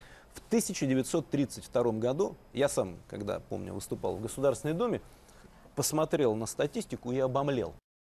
Звуки высказываний Медведева
В 1932 году Медведев выступил в ГосДуме